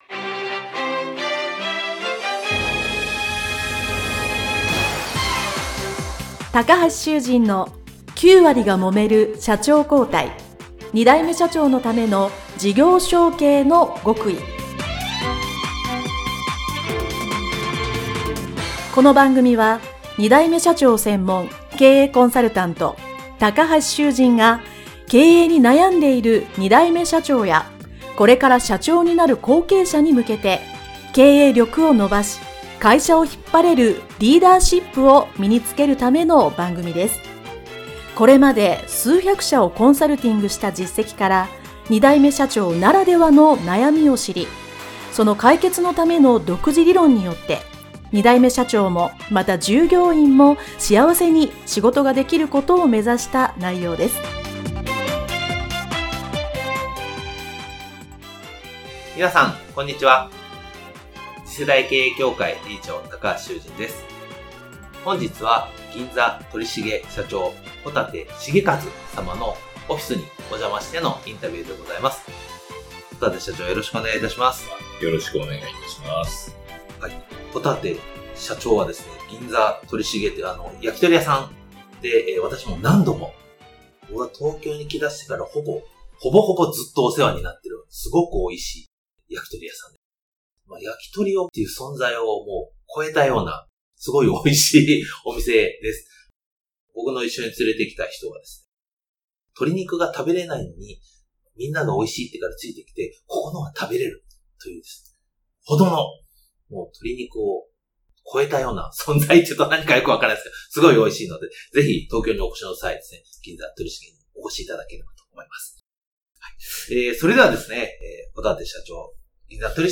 【インタビュー前編】